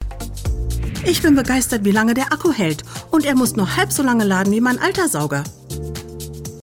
Female
Adult (30-50)
warm, serious, entertaining, trustworthy, soothing, exciting, commercial-like, e-learning, wide range
Natural Speak
3 Testimonials German